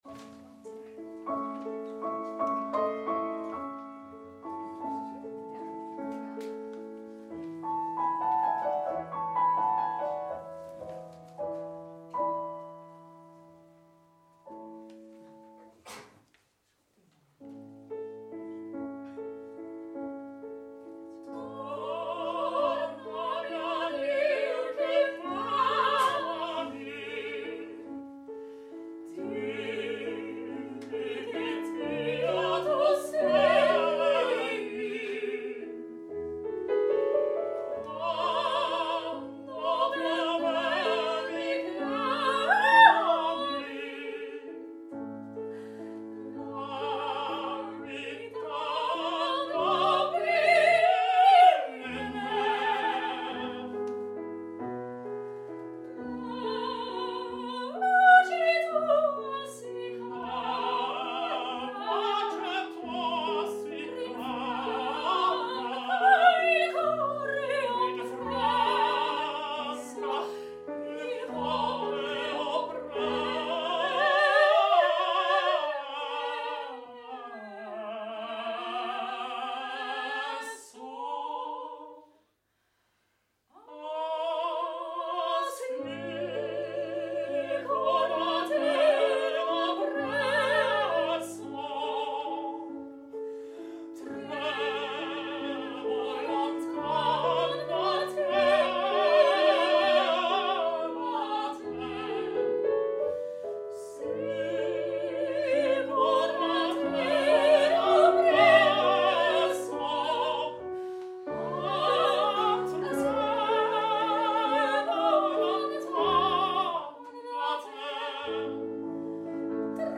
BEGELEIDING LIED EN OPERA/ OPERETTE ARIA’S